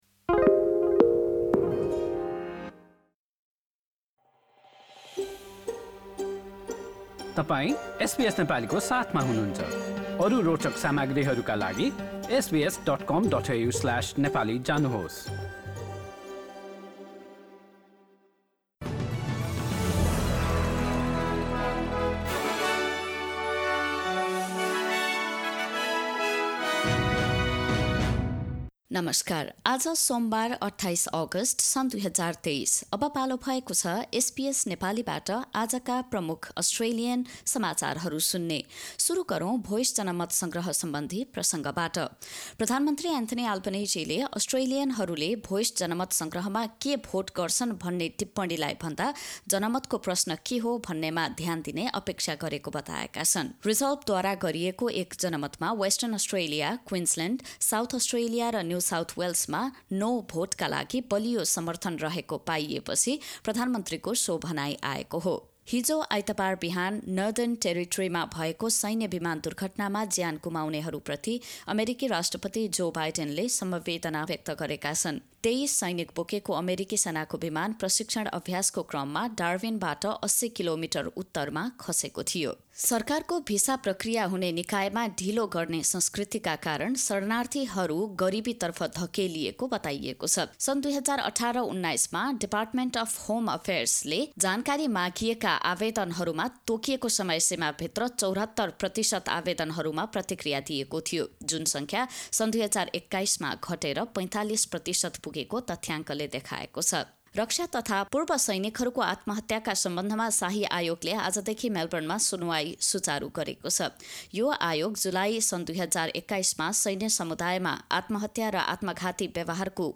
एसबीएस नेपाली प्रमुख अस्ट्रेलियाली समाचार: सोमवार, २८ अगस्ट २०२३